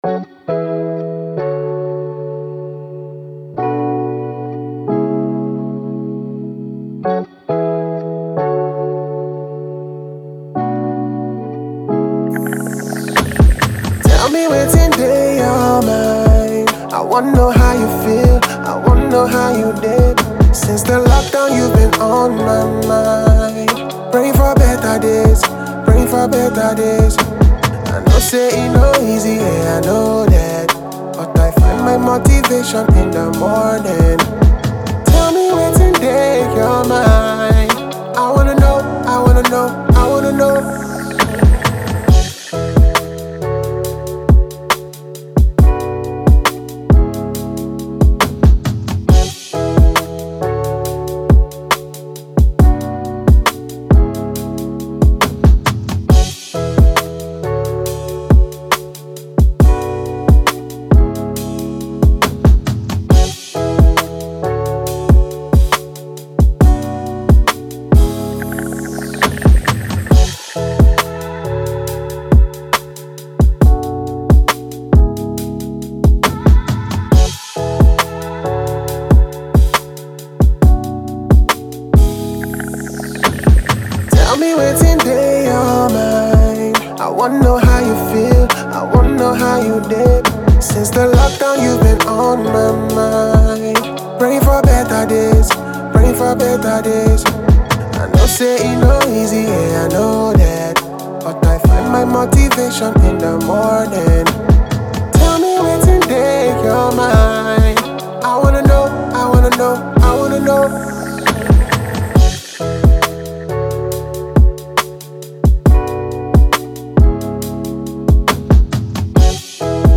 R&B, Soul
A Maj